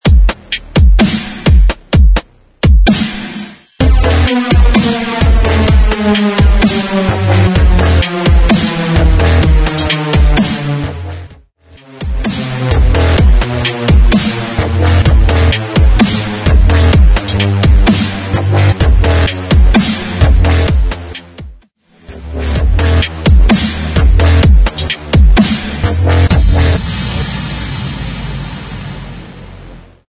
128 BPM
Dubstep